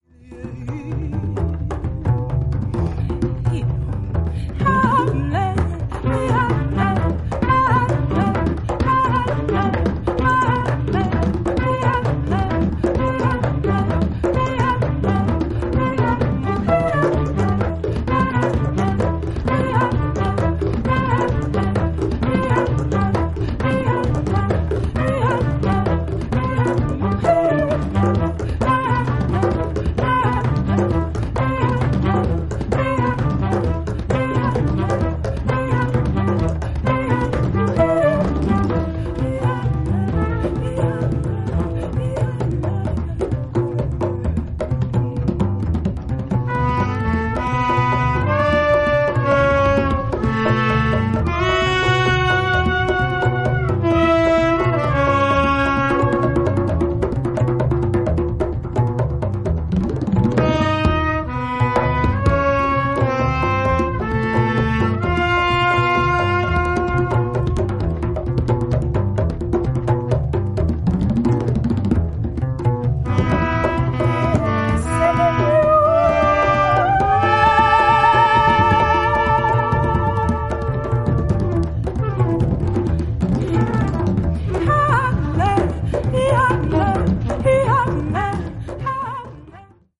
アンティル諸島出身のベーシスト/シンガー